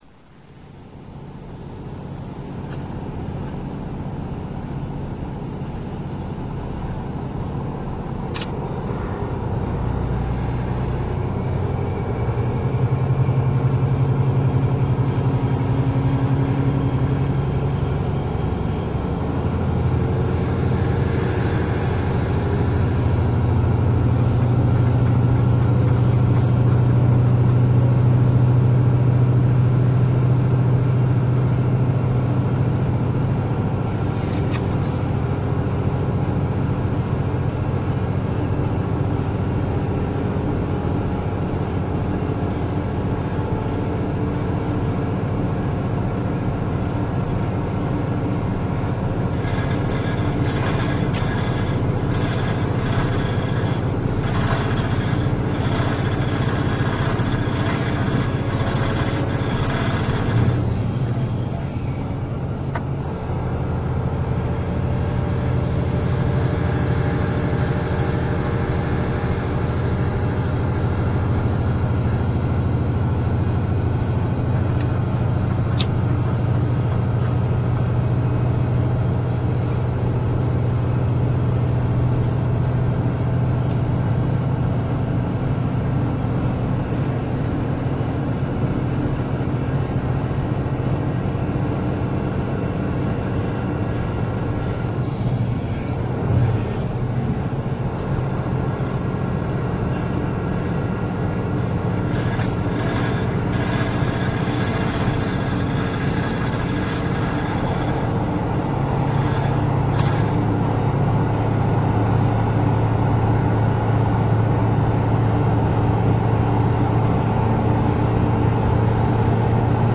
キハ183形200番代加速音[k183-200a.ra/340KB]
エンジン：N-DMF13HZC(420PS)×1
変速機：N-DW14C(直結2段式)